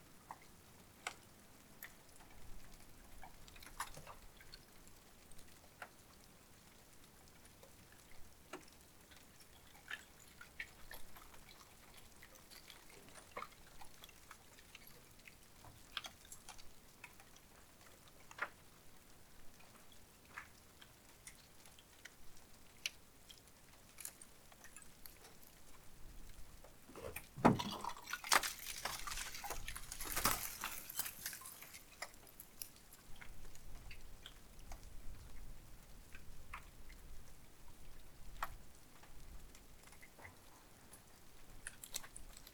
The sound of surging ice at Vallåkrabreen, Svalbard in May 2023.